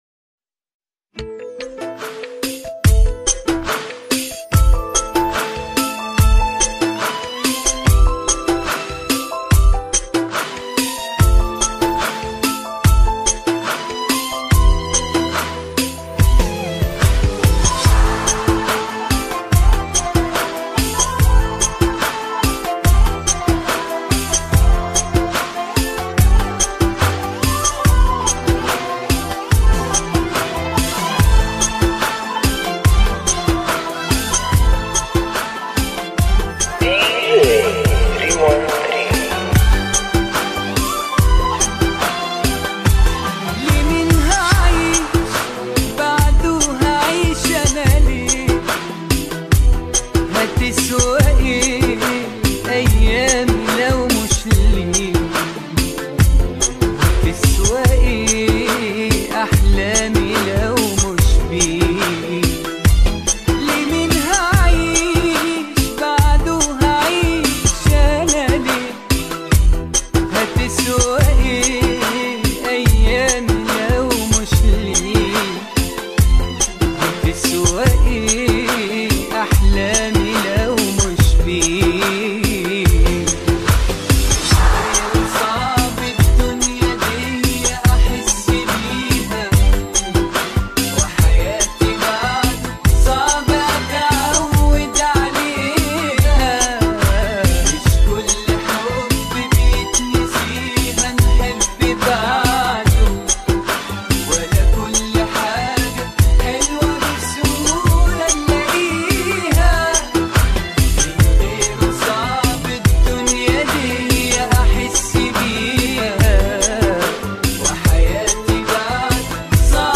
ريمگـس